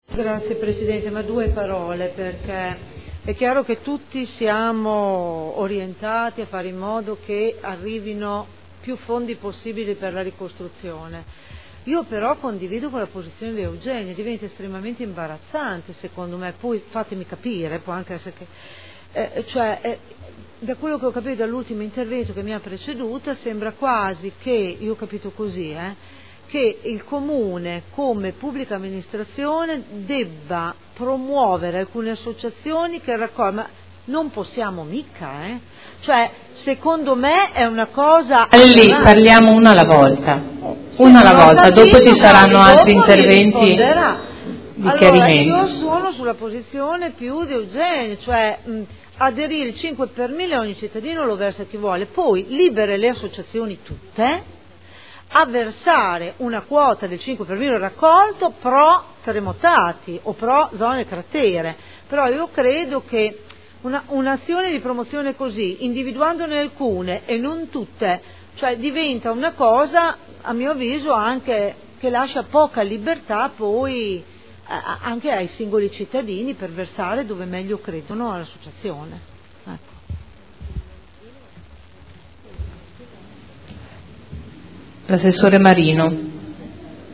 Cinzia Cornia — Sito Audio Consiglio Comunale